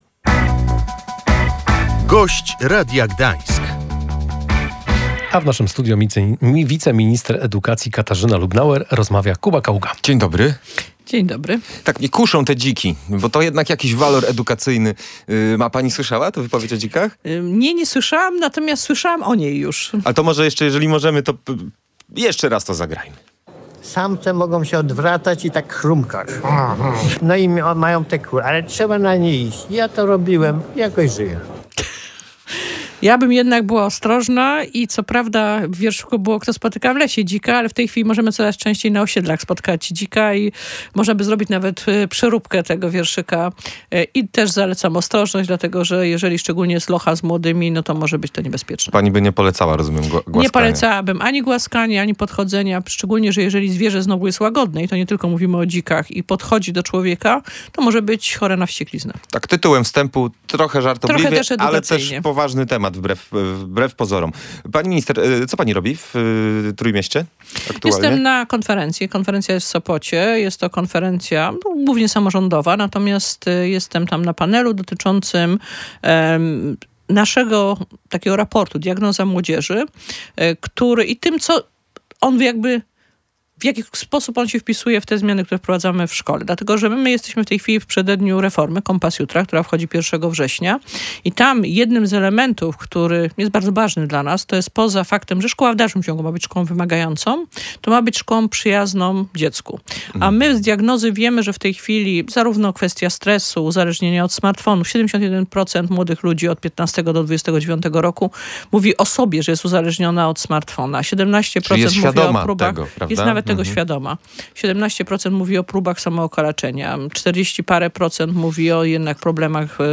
Katarzyna Lubnauer mówiła w Radiu Gdańsk, że obawy duchownych są nieuzasadnione, a przedmiot edukacja zdrowotna jest konieczny.